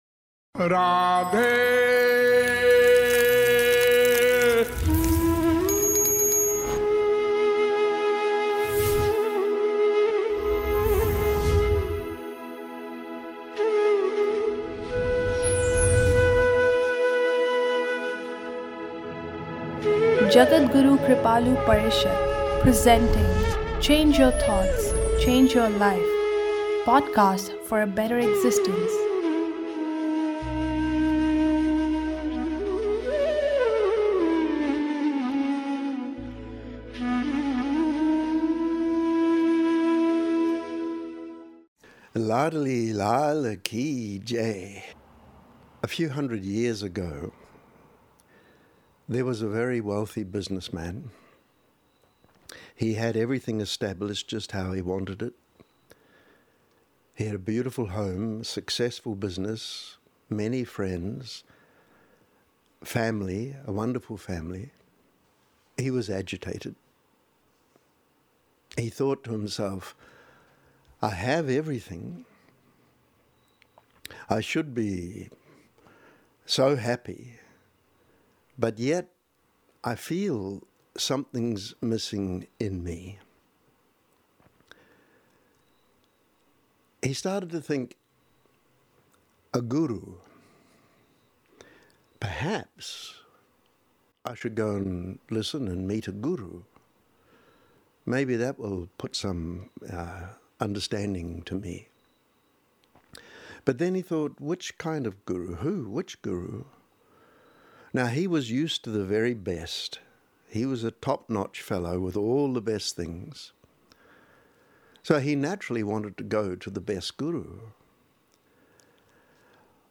In this lecture